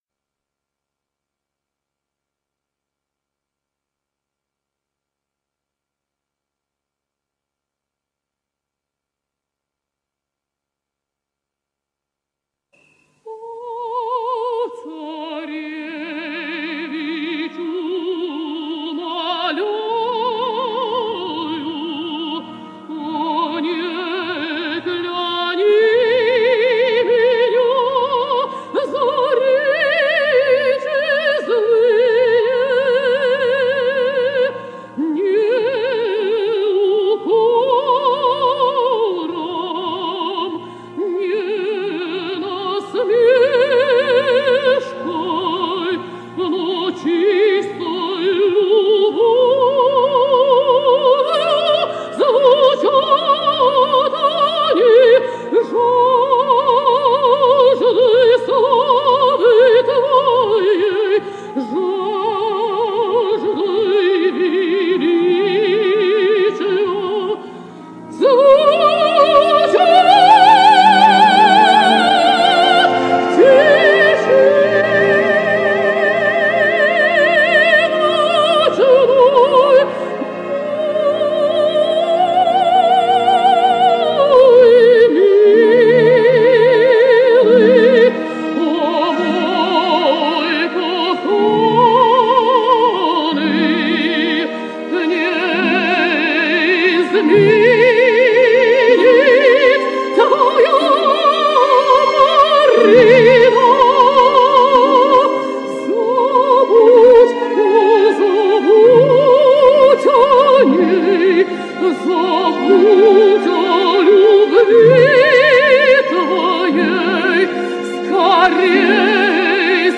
Vladimir Atlantov singsBoris Godunov:
Dmitrij! Tsarevich!, with Elena Obraztsova